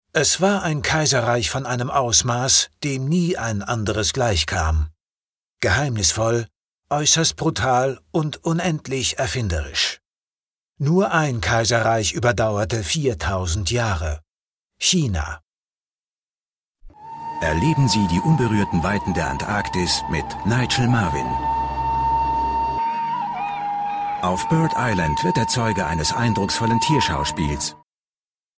Profi Sprecher deutsch. Synchronsprecher, Werbesprecher. Sprecher für Hörspiele, Hörbücher, Imagefilm u.a.
Sprechprobe: Industrie (Muttersprache):